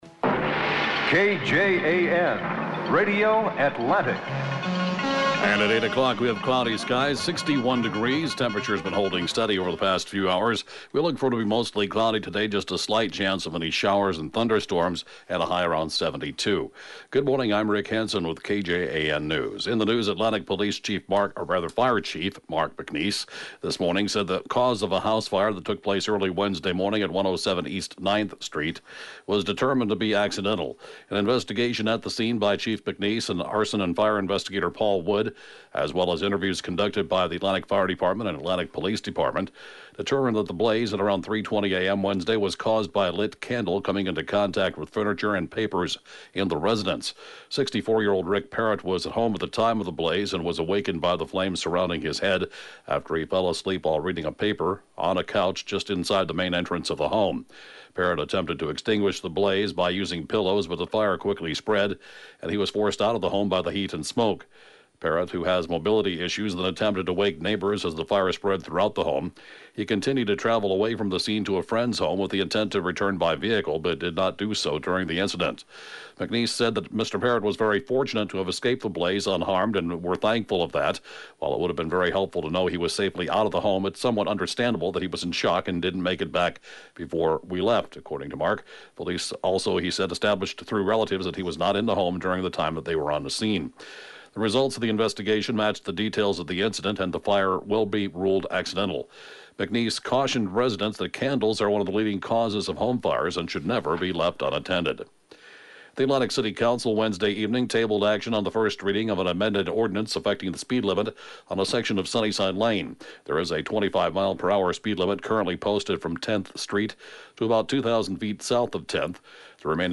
(Podcast) KJAN Morning News & Funeral report, 10/15/2018